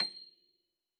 53r-pno26-C6.wav